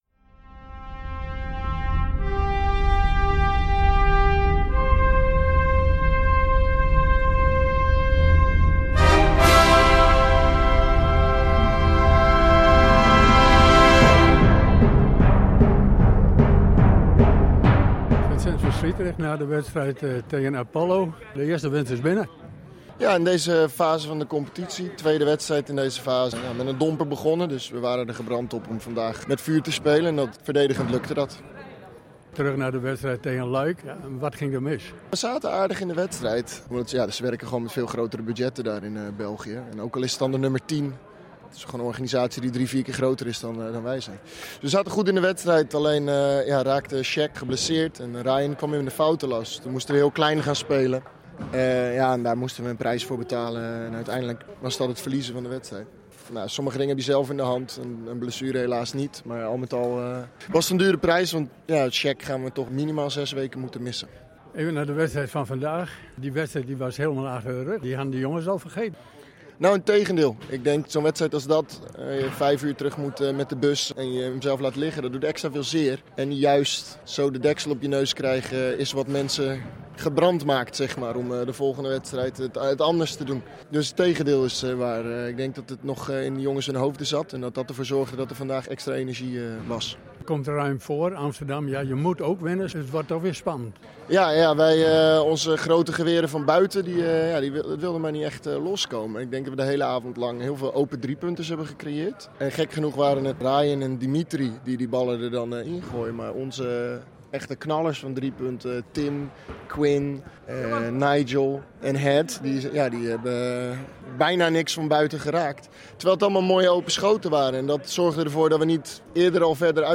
Wedstrijd Verslag